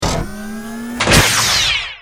battlesuit_tinylaser.wav